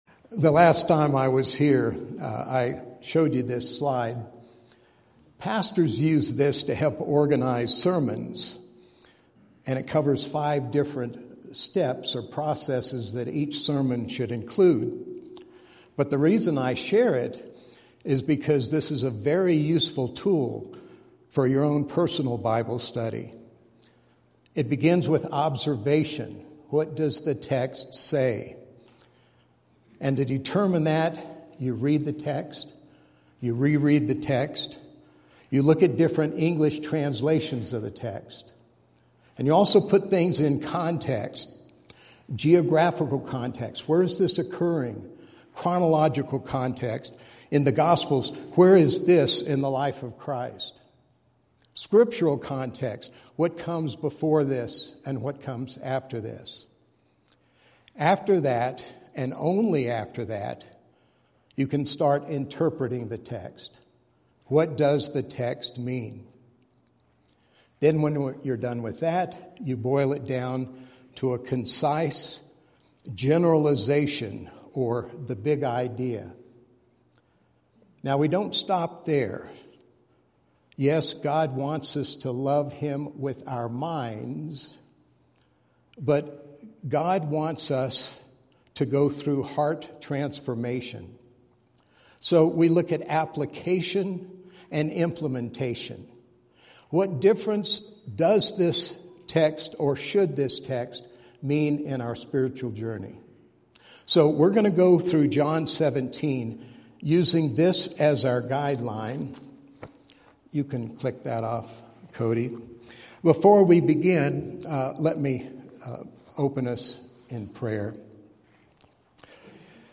Sermons
Service: Sunday Morning